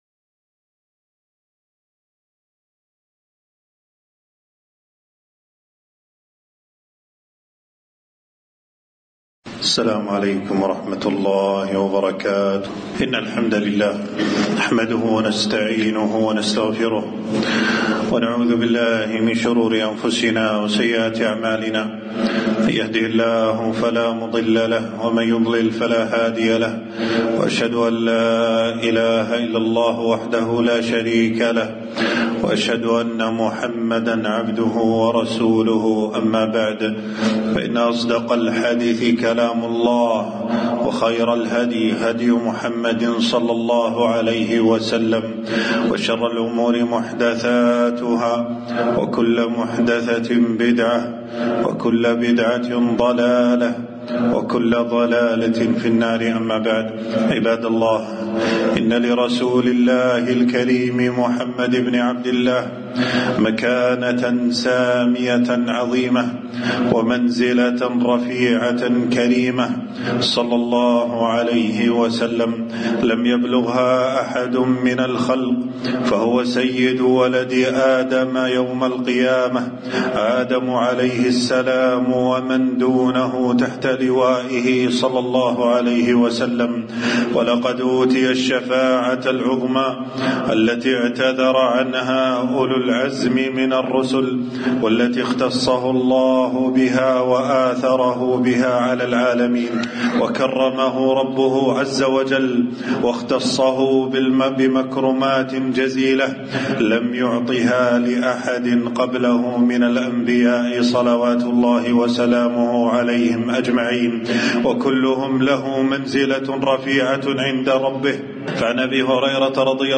خطبة - حقيقة محبة الرسول صلى الله عليه وسلم والتحذير من الغلو فيه والاحتفال بمولده